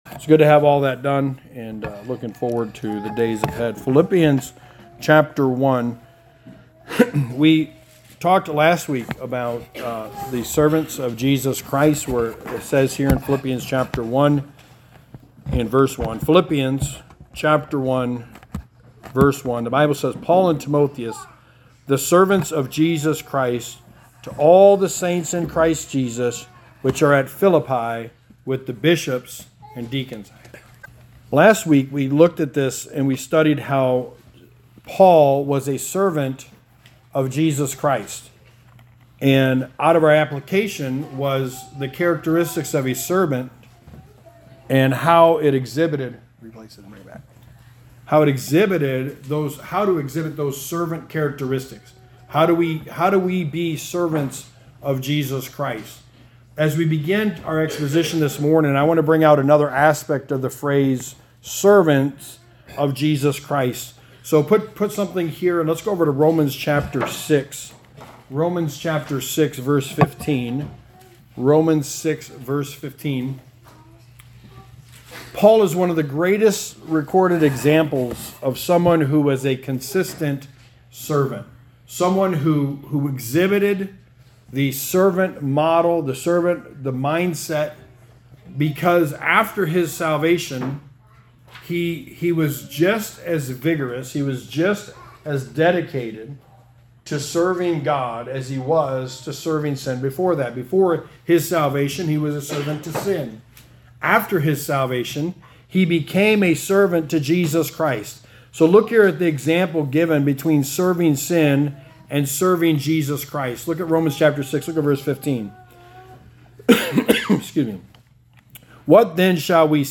Sermon 3: The Book of Philippians: The Peace of God
Service Type: Sunday Morning